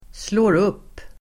Uttal: [slå:r'up:]